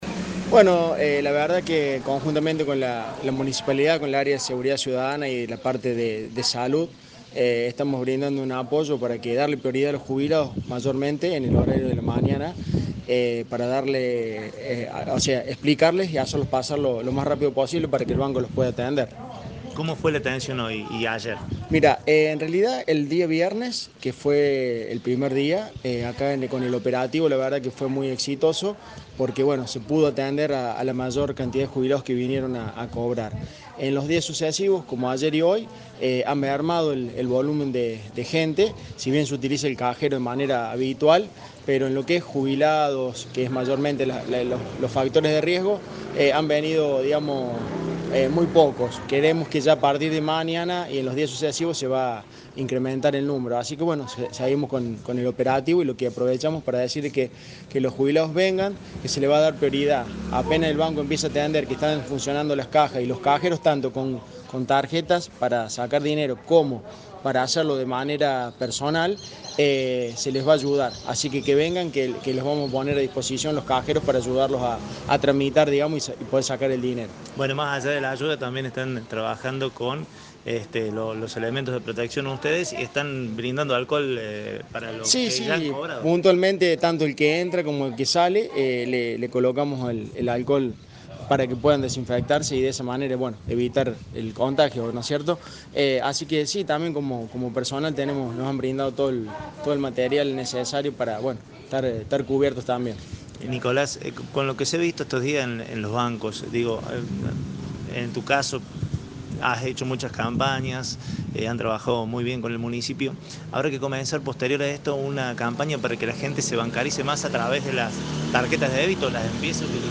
El funcionario habló con Cadena 3 Villa María sobre la tarea realizada.